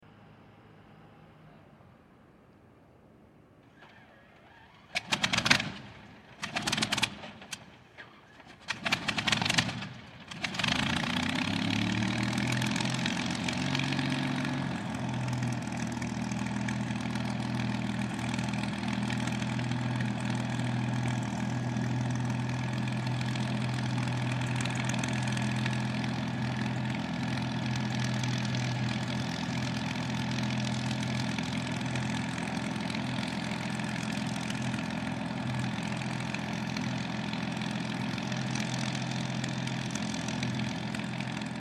Messerschmidt BF-109 startup
A restored Messerschmitt Bf-109E-3 starts up its Daimler-Benz 601 engine. This is part of the Obsolete Sounds project , the world’s biggest collection of disappearing sounds and sounds that have become extinct – remixed and reimagined to create a brand new form of listening.